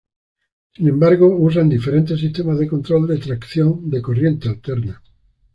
Pronunciado como (IPA) /tɾaɡˈθjon/